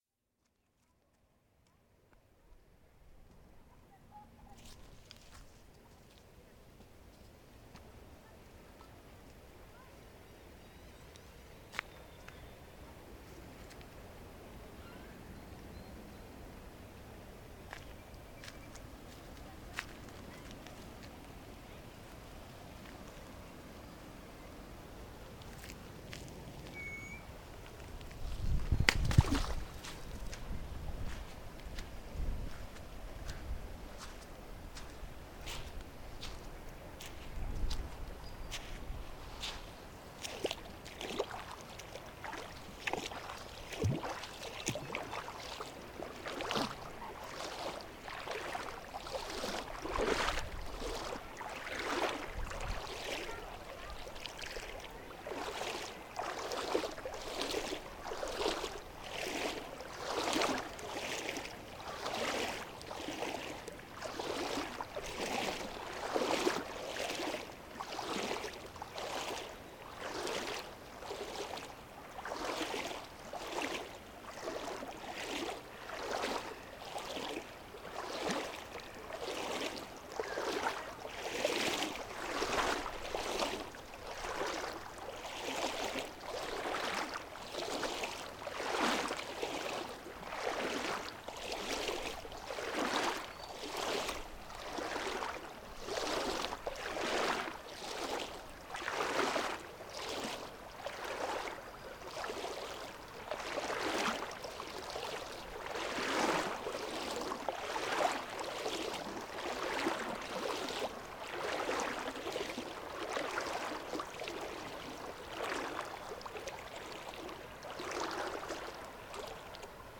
En el río, torrentes de agua y piedras sonoras. (Paisajismo sonoro)